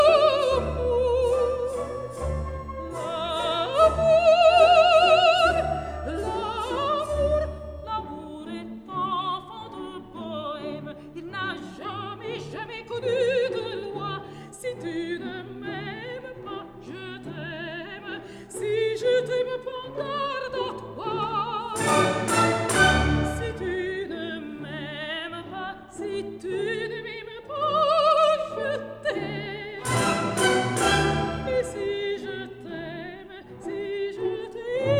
Classical Opera
Жанр: Классика